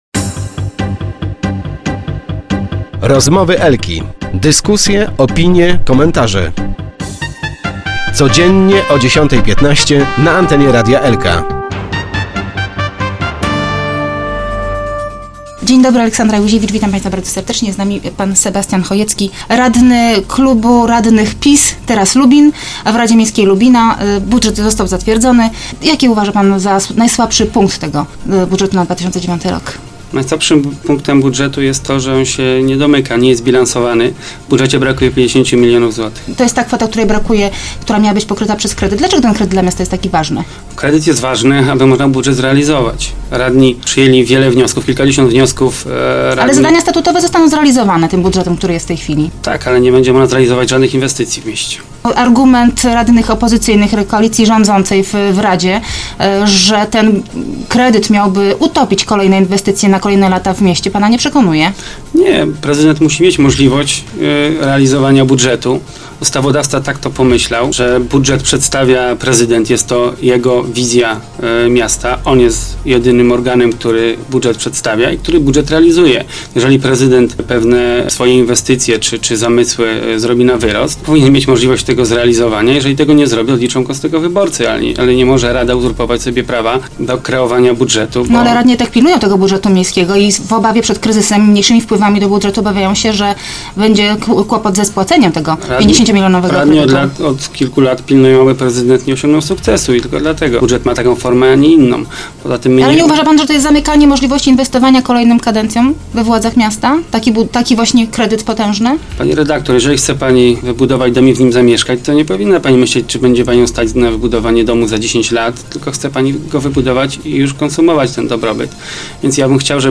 Start arrow Rozmowy Elki arrow Chojecki: to budżet stagnacji